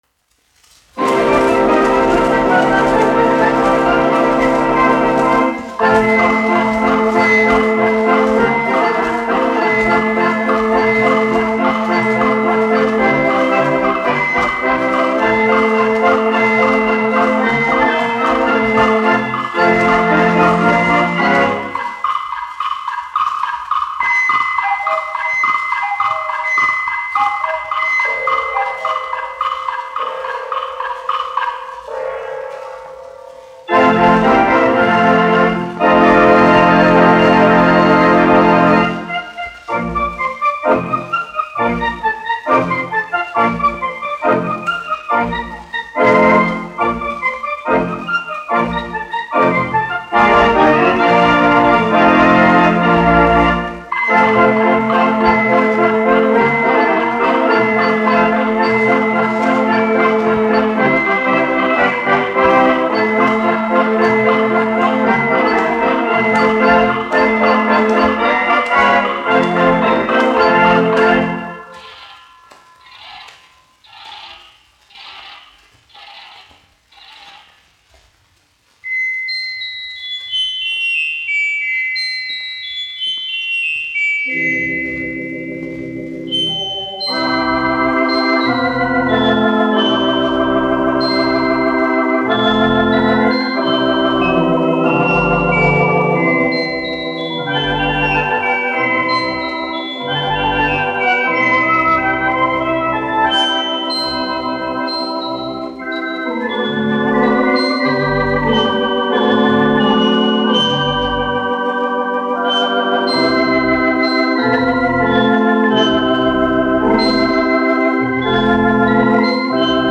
1 skpl. : analogs, 78 apgr/min, mono ; 25 cm
Populārā instrumentālā mūzika
Kino ērģeles
Skaņuplate